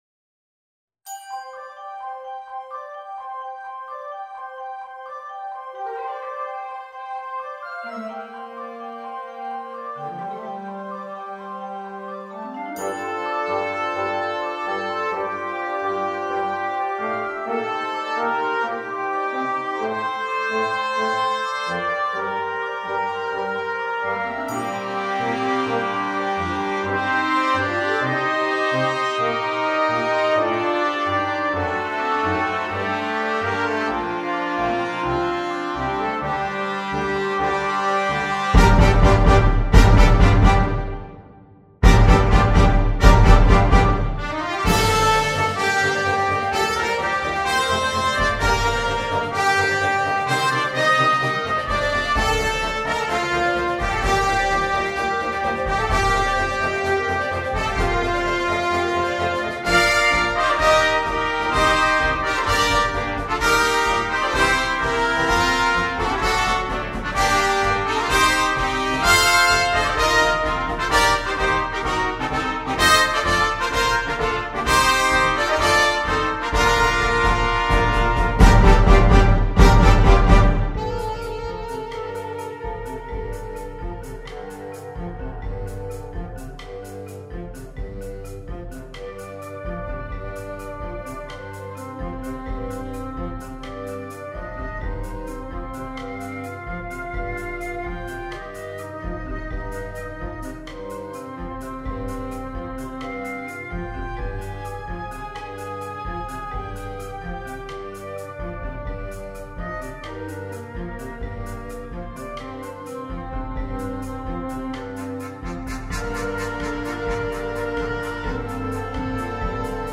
for concert band
MIDI mockup recording